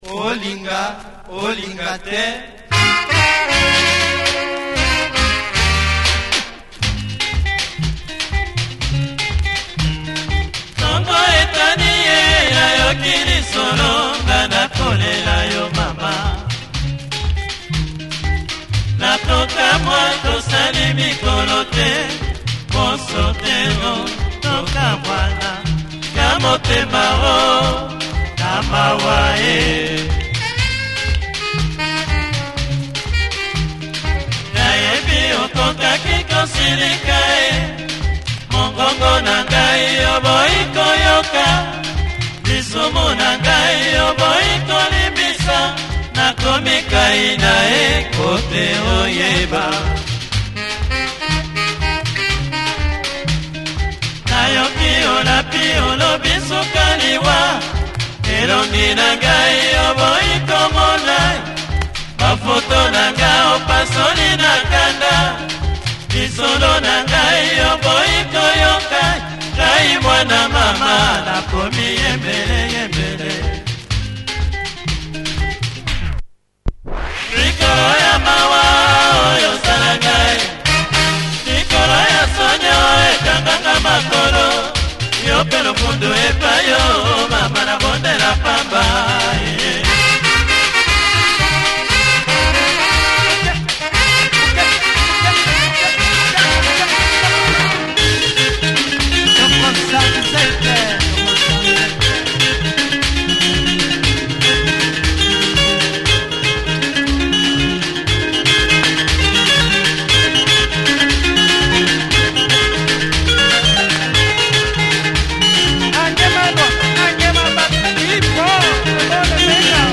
Nice lingala.